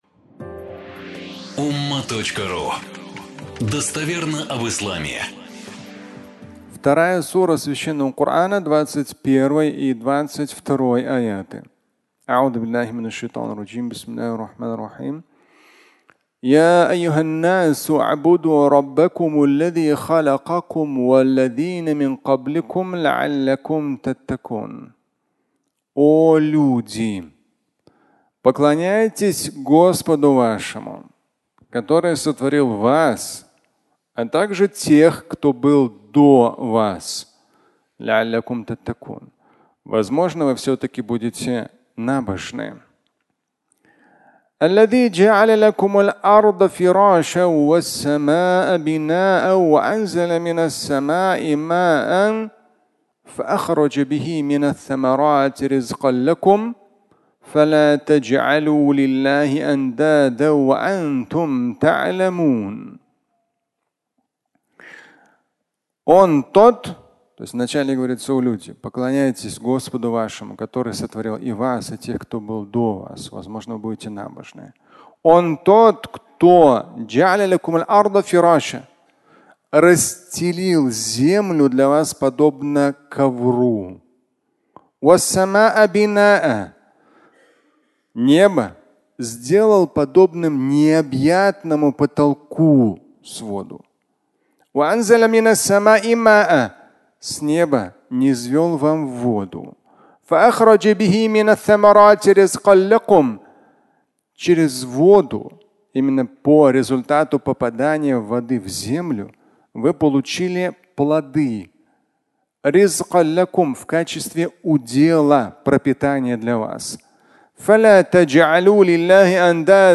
Бог заботится о тебе! (аудиолекция)
Фрагмент пятничной проповеди